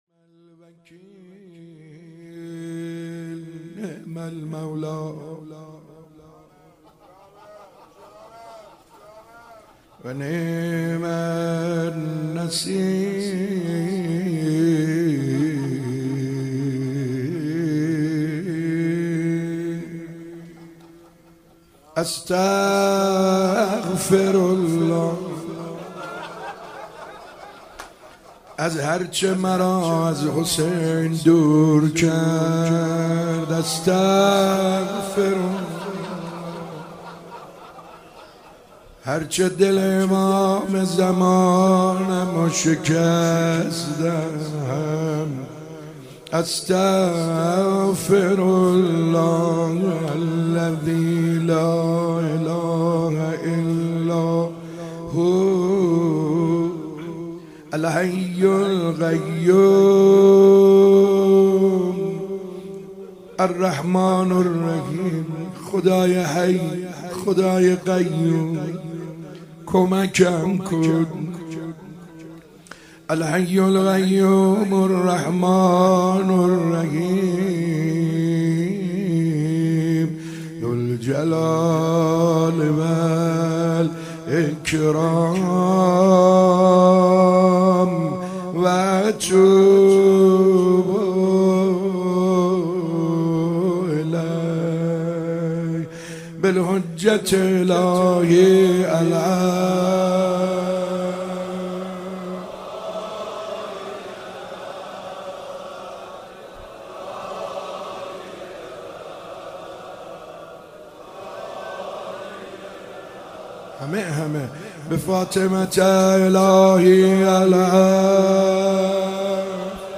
محفل عزاداری روز اول محرم
سخنرانی